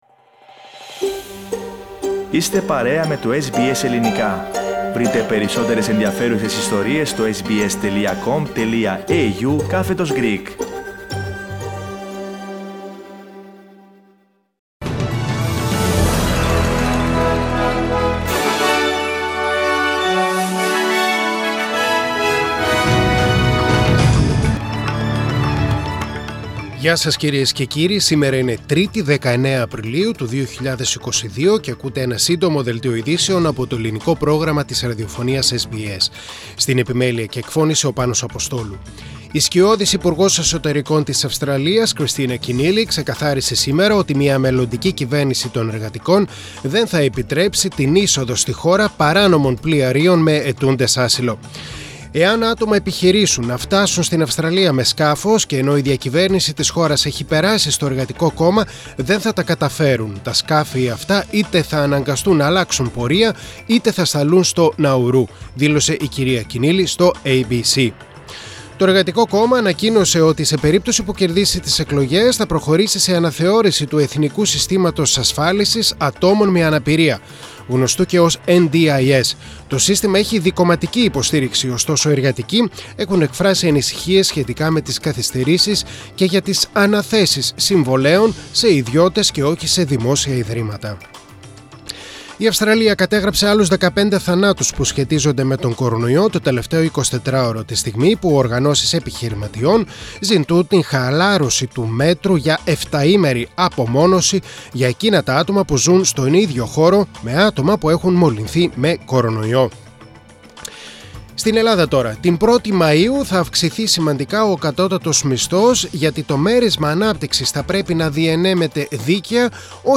Greek News Flash.